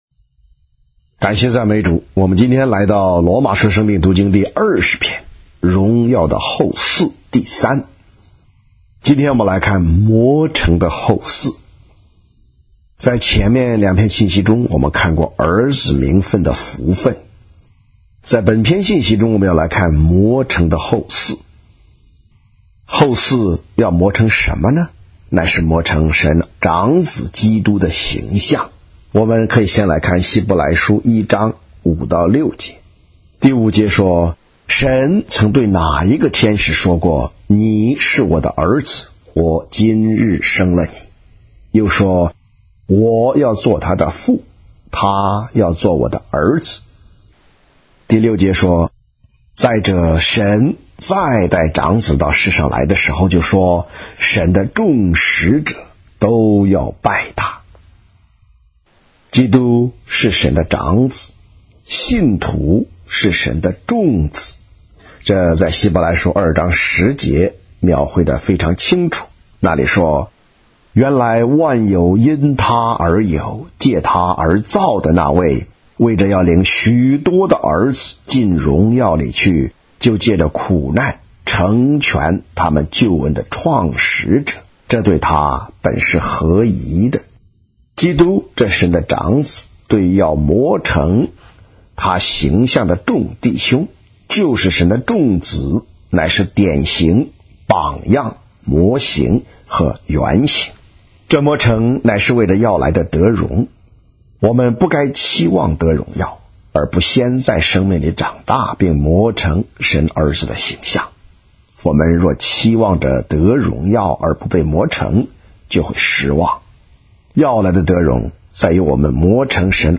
追求日期 2/10/2025 👆羅馬書生命讀經 第二十篇全篇 👆延伸讀經段落及註解 ：羅 八28～30 🔉語音播放生命讀經 📃生命讀經(紙本)：P280-P284L8 📃新約聖經恢復本(紙本)：P604-P606 第 二十篇 榮耀的後嗣（三） 貳 模成的後嗣 一 長子...